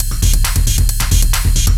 DS 135-BPM B2.wav